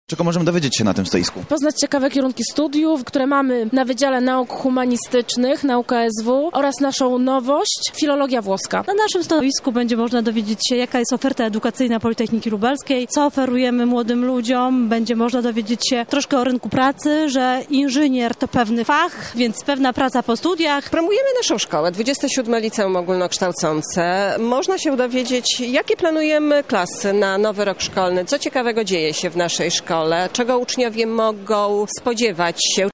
Targi odwiedził nasz reporter